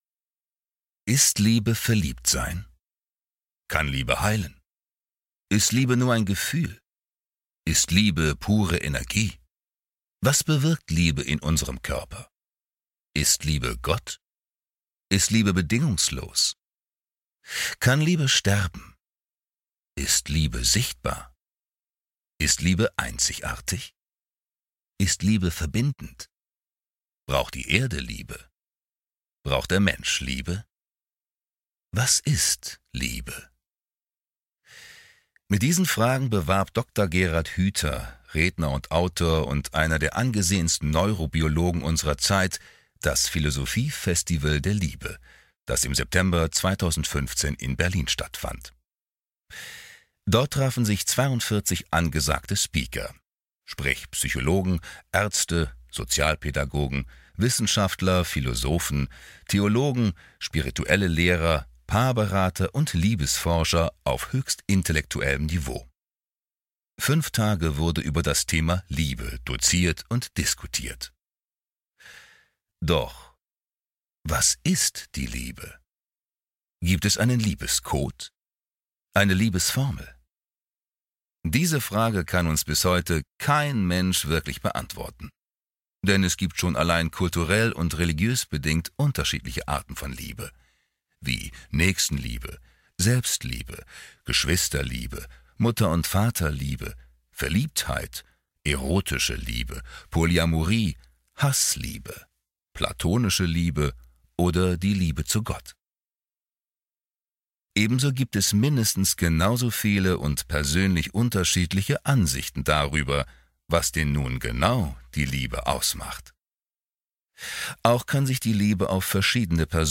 Sprechprobe: Industrie (Muttersprache):
Sachbuch_Was ist Liebe.mp3